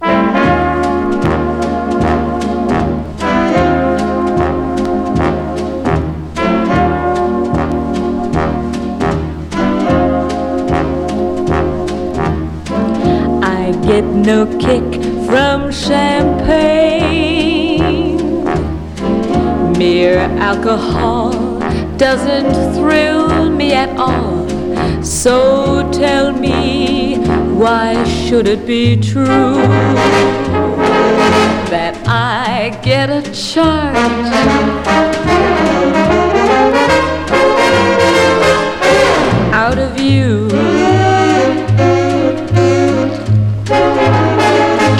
Jazz, Easy Listening, Vocal　USA　12inchレコード　33rpm　Mono